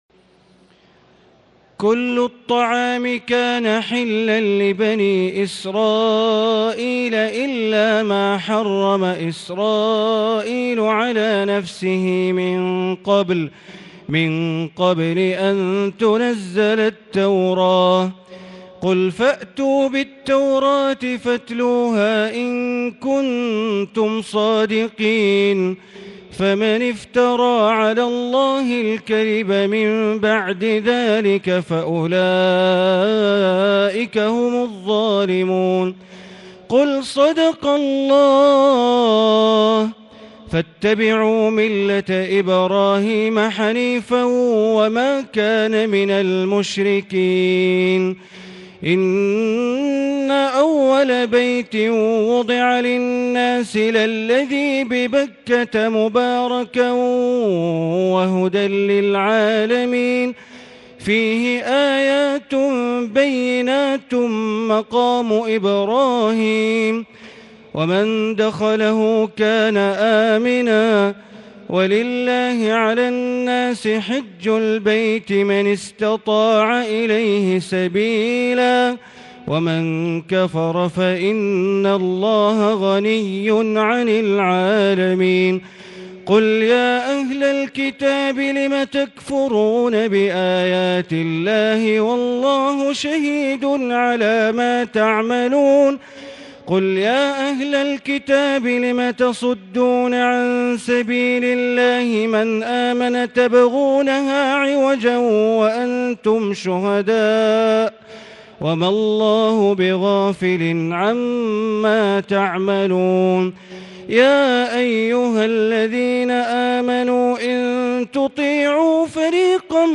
تهجد ليلة 24 رمضان 1440هـ من سورة آل عمران (93-185) Tahajjud 24 st night Ramadan 1440H from Surah Aal-i-Imraan > تراويح الحرم المكي عام 1440 🕋 > التراويح - تلاوات الحرمين